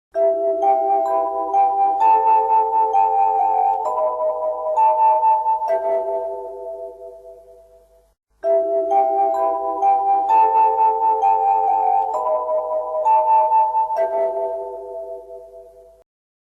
Советское радио - Вариант 2 (чистый звук)